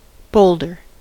boulder: Wikimedia Commons US English Pronunciations
En-us-boulder.WAV